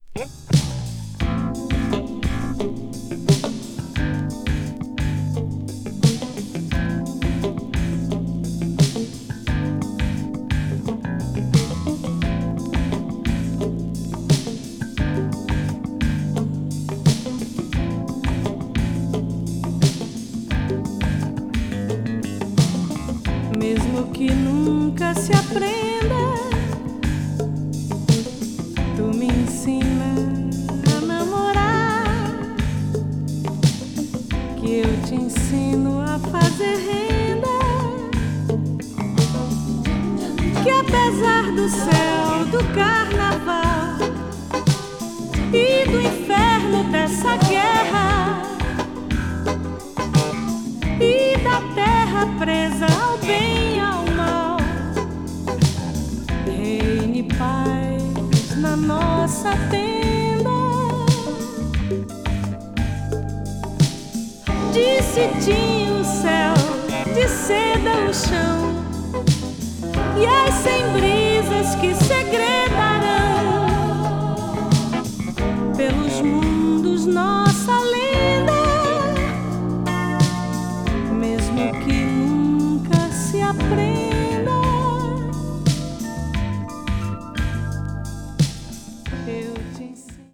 a.o.r.   brazil   mellow groove   mpb   pop   world music